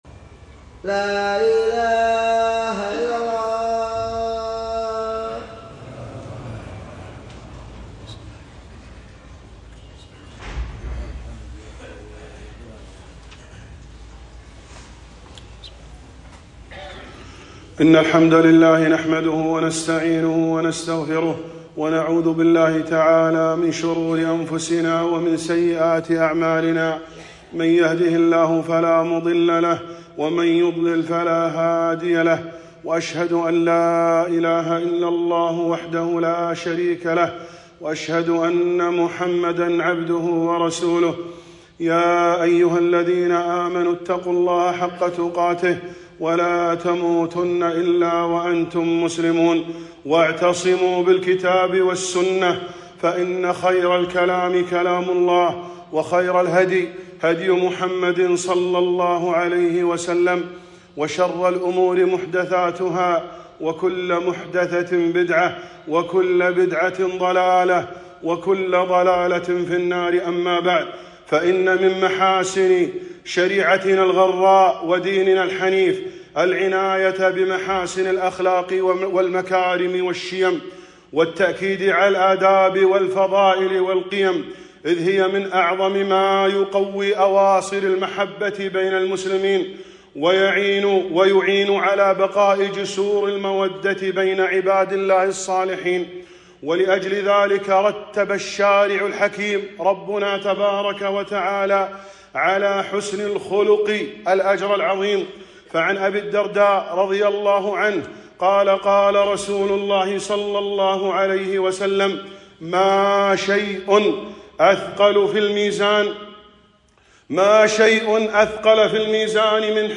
خطبة - كفُّ الأذى عن المسلمين